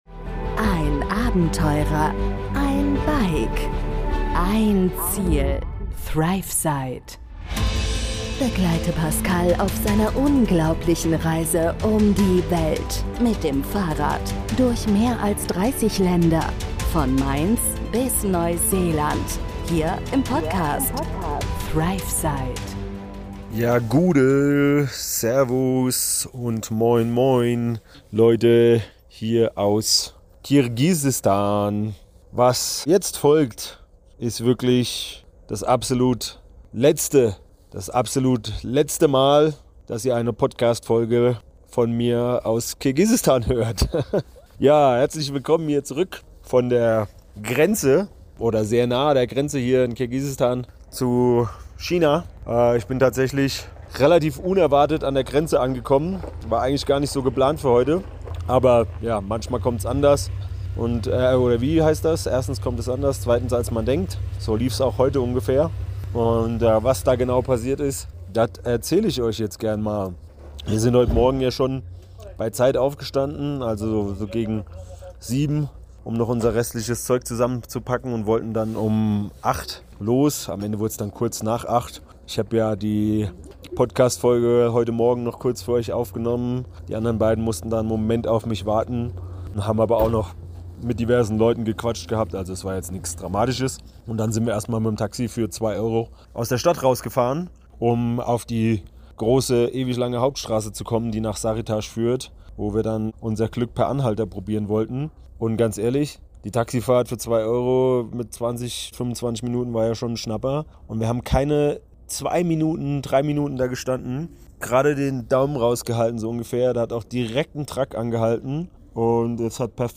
Hey Leute, willkommen zur letzten Folge direkt von der Grenze zu China!